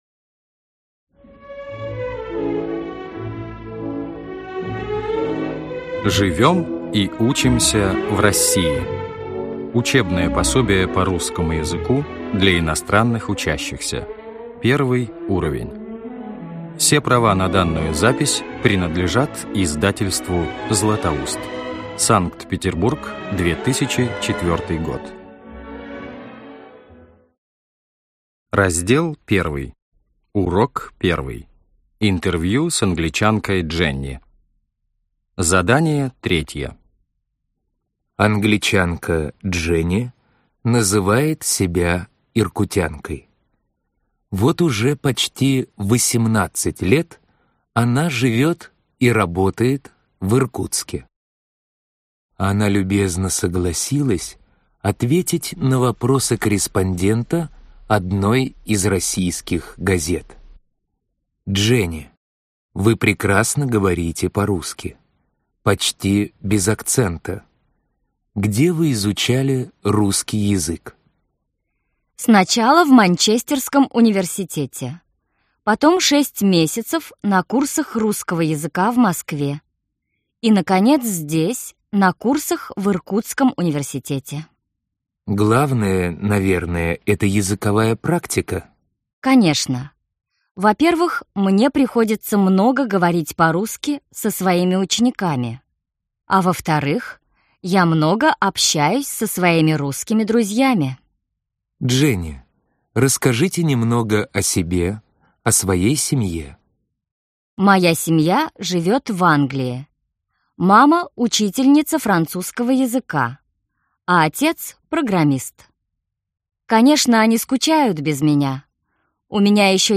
Аудиокнига Живём и учимся в России. Учебное пособие по русскому языку для иностранных учащихся (I уровень) | Библиотека аудиокниг